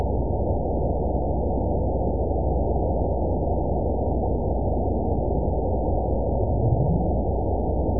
event 922533 date 01/27/25 time 15:18:59 GMT (4 months, 3 weeks ago) score 8.44 location TSS-AB02 detected by nrw target species NRW annotations +NRW Spectrogram: Frequency (kHz) vs. Time (s) audio not available .wav